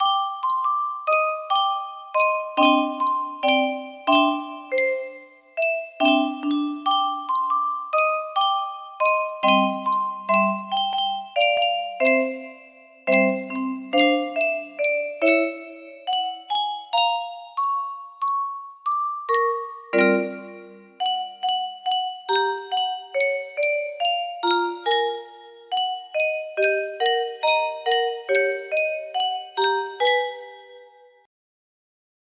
Unknown Artist - Untitled music box melody
Grand Illusions 30 (F scale)